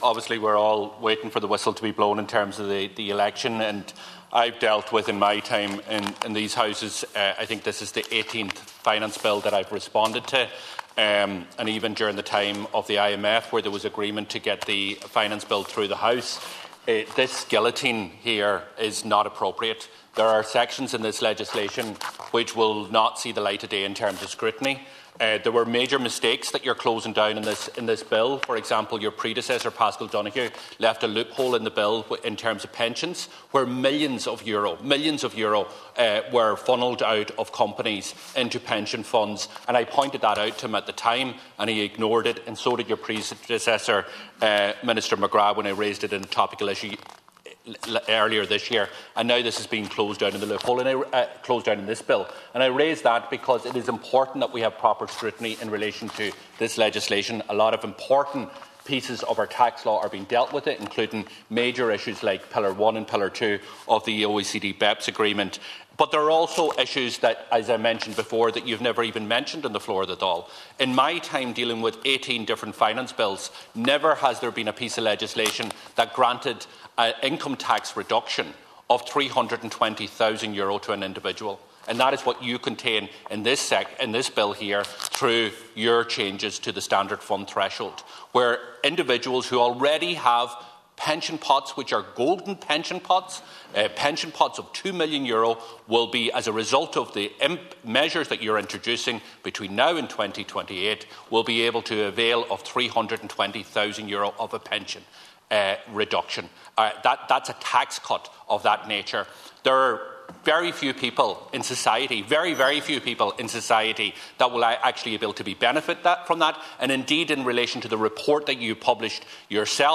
A debate on the Finance Bill is ongoing in the Dail.
Sinn Fein Finance Spokesperson, Donegal Deputy Pearse Doherty expressed his opposition to the bill passing in its current form: